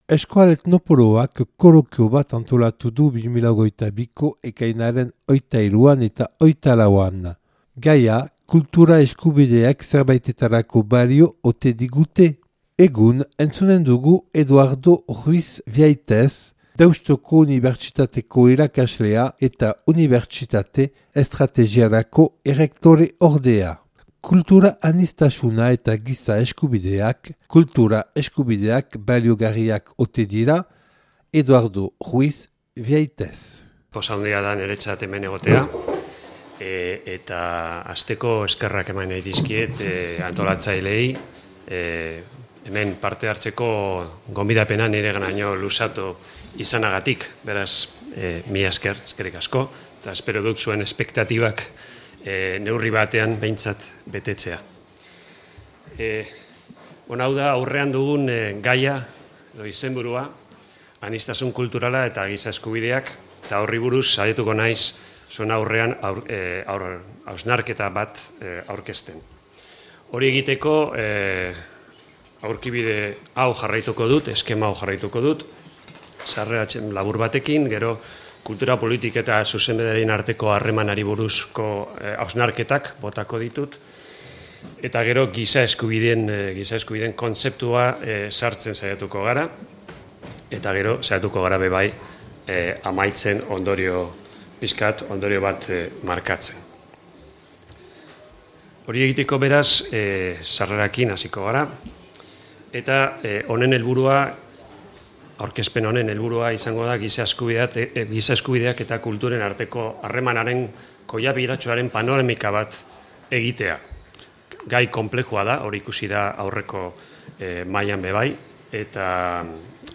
[Euskal Etnopoloaren kolokia Baionako Euskal Museoan 2022. Ekainaren 23-24]